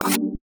UIMisc_Tonal Short 03.wav